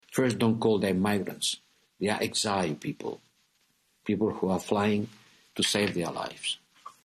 În discursul său pentru Parlamentul European, Josep Borrell a spus că aproximativ 100 de angajați ai Uniunii Europene și 400 de afgani care lucrează cu UE și familiile lor au fost evacuați, dar că încă 300 de afgani încearcă să plece.